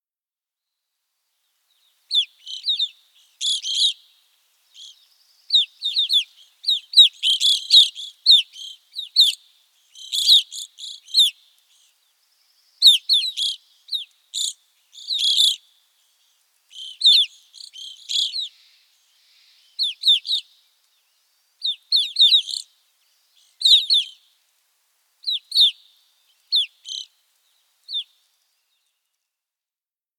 Evening Grosbeak
How they sound: Their call note is a burry chirp .